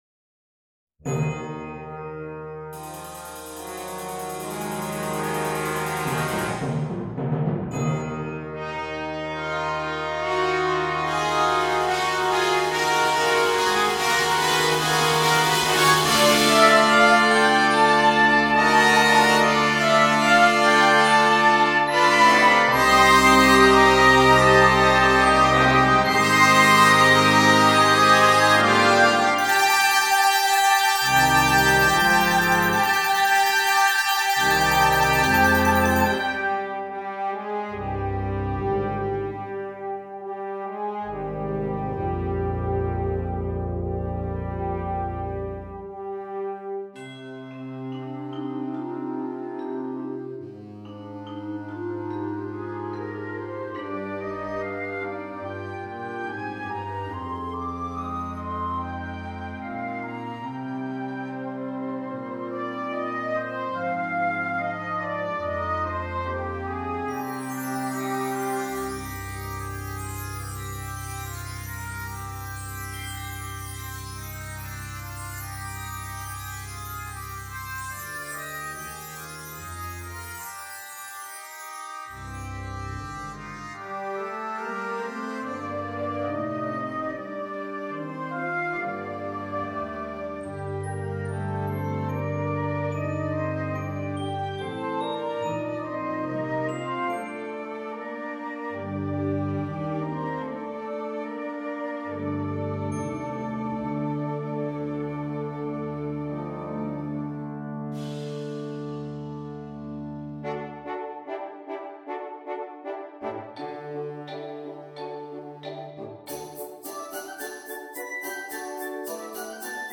Besetzung Blasorchester